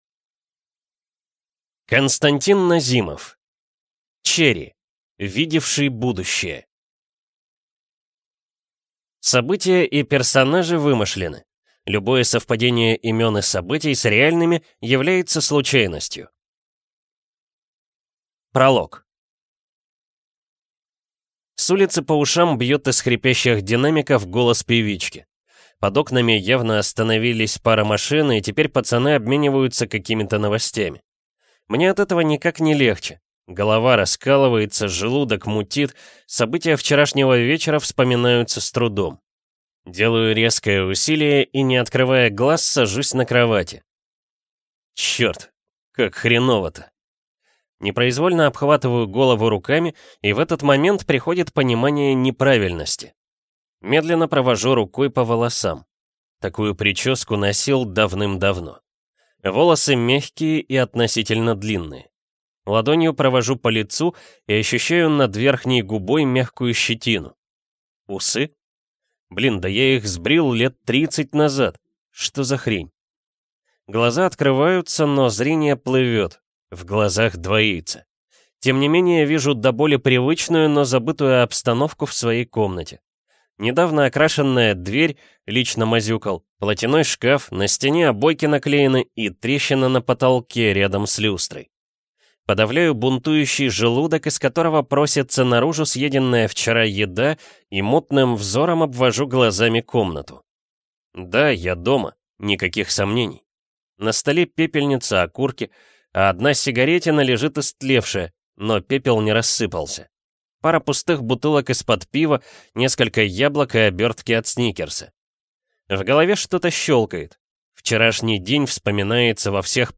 Аудиокнига Cherry. Видевший будущее | Библиотека аудиокниг